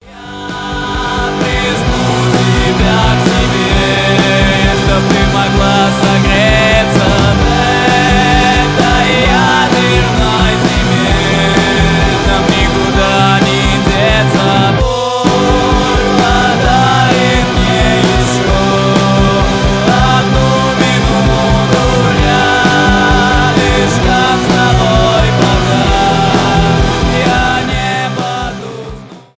панк-рок